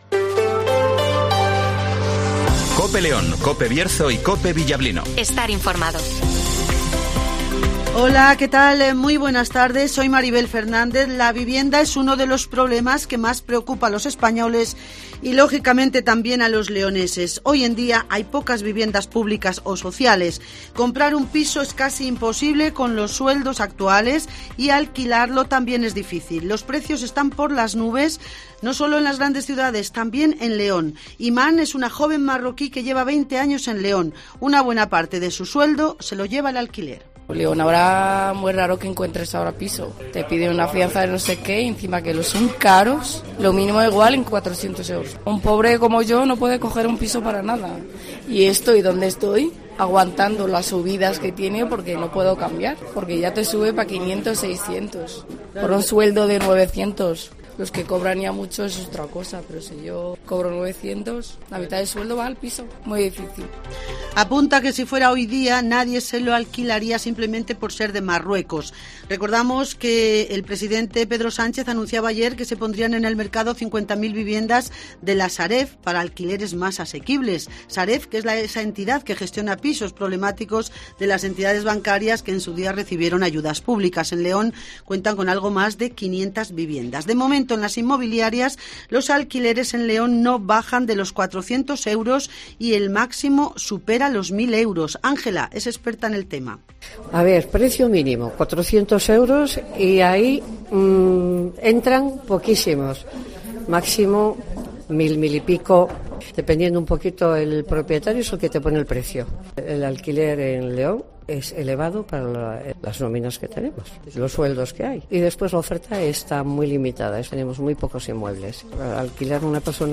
INFORMATIVOS
Repaso a la actualidad informativa de León capital, del Bierzo y del resto de la provincia. Escucha aquí las noticias con las voces de los protagonistas.